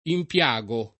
impiago [ imp L#g o ], ‑ghi